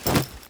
Polaris/sound/items/drop/hat.ogg at 948d43afecadc272b215ec2e8c46f30a901b5c18